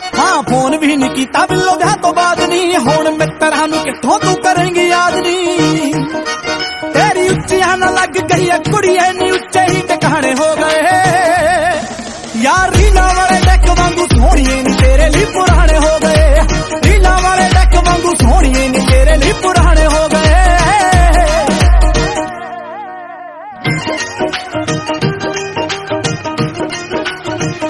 Enjoy this Haryanvi DJ beat as your ringtone.